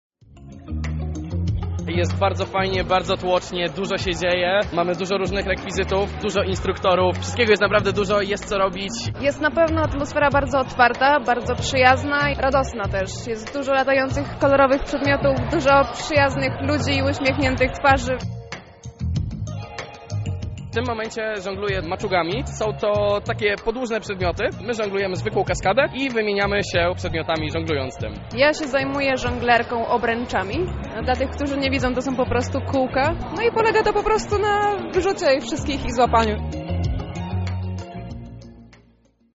Nasza reporterka sprawdziła jak wyglądał pierwszy dzień zajęć: